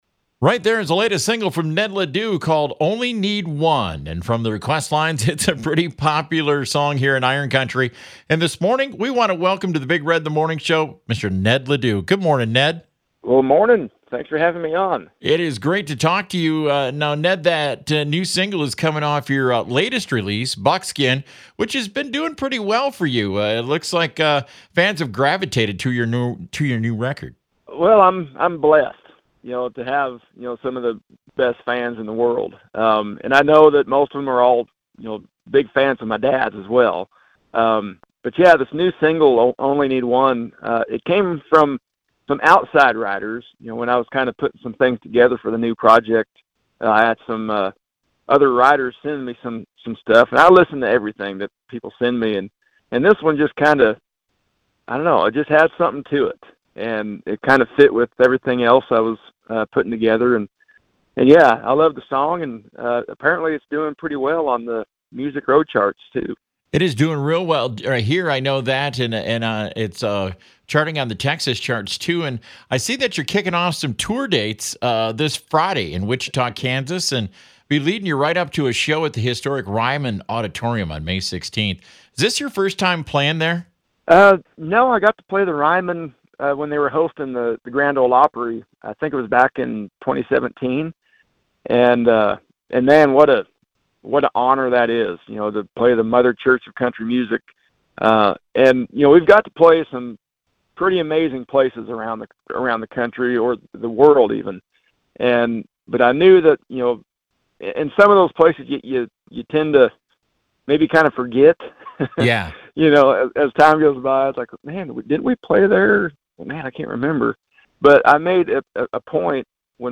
Ned LeDoux Interview